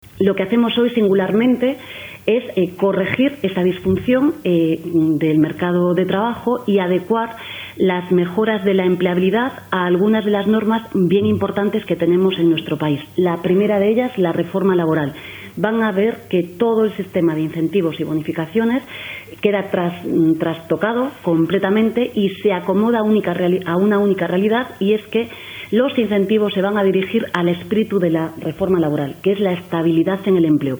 Yolanda Díaz, explicó formato MP3 audio(0,59 MB) en la rueda de prensa posterior al Consejo de Ministros que “todo el sistema de incentivos y bonificaciones queda trastocado y se dirige al espíritu de la reforma laboral, que es la estabilidad en el empleo”.